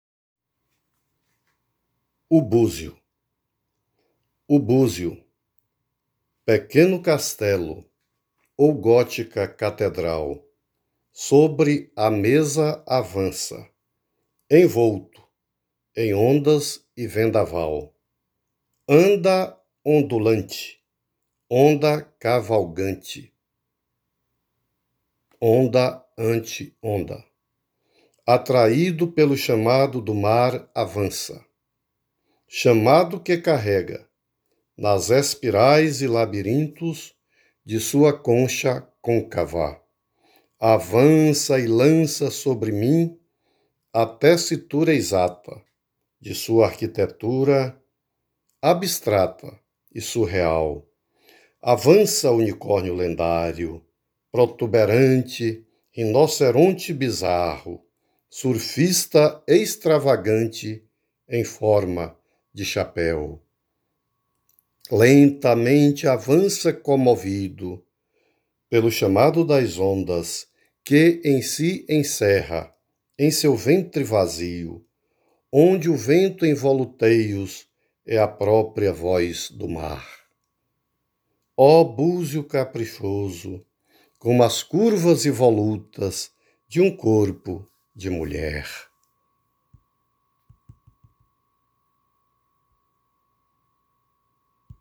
01:25:00   Verso Recitado